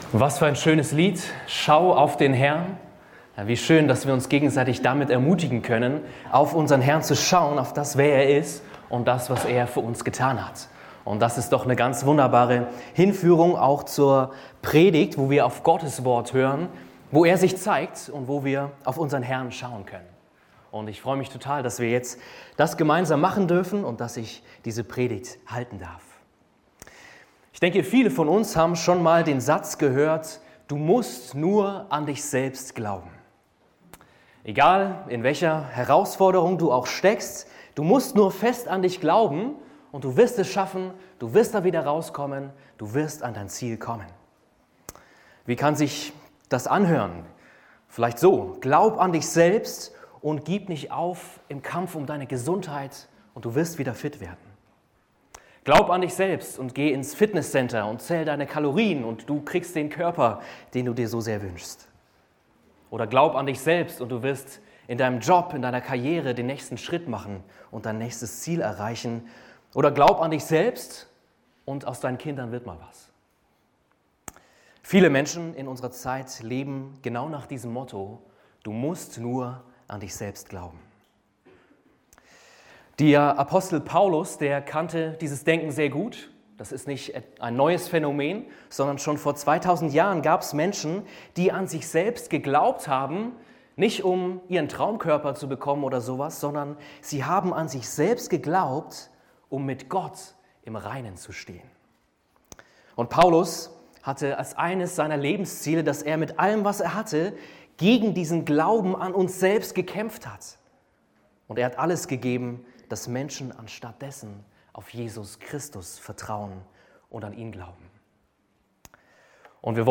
predigte